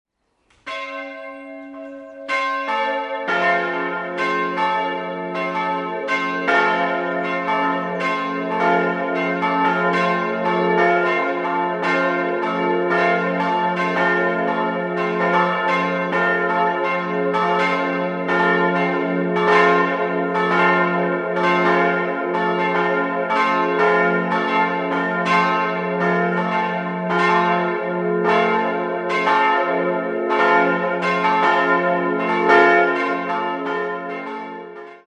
Jahrhundert unter den Johannitern um einen polygonalen Chor und Seitenkapellen erweitert wurde. 3-stimmiges F-Dur-Geläute: f'-a'-c'' Die Glocken wurden im Jahr 1950 von der Gießerei Hamm in Regensburg gegossen und erklingen in den Tönen f'+2, a'+2 und c''+12.